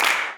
CLAP167.wav